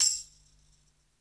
3 perc -tambourine.wav